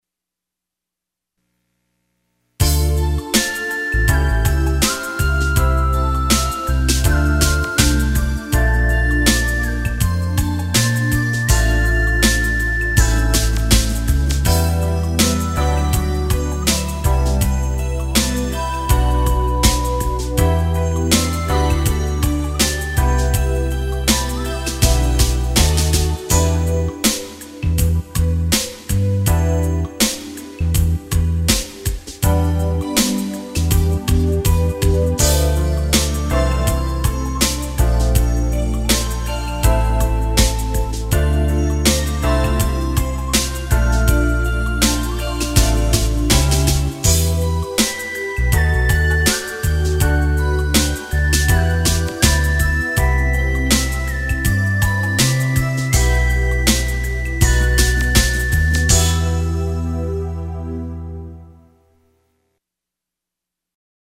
sounds like, well, slow R&B